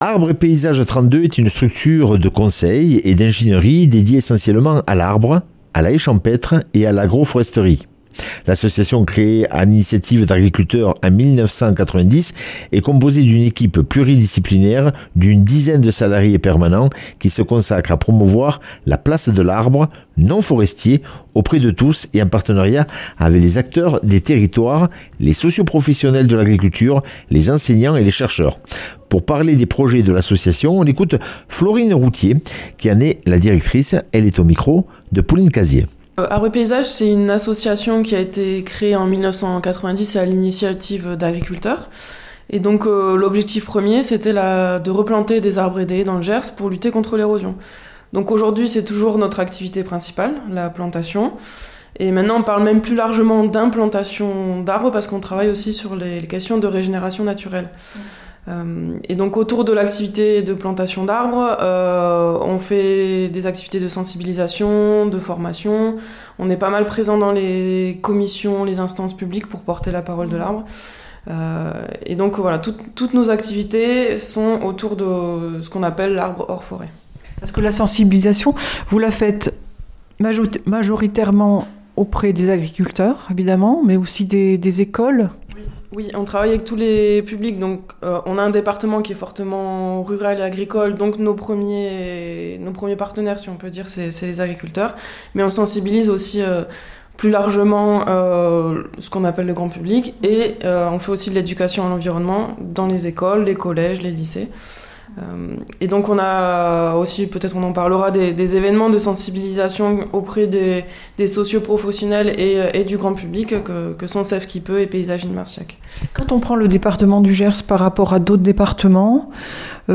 Interview et reportage du 21 janv.